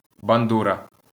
A bandura (Ukrainian: бандура [bɐnˈdurɐ]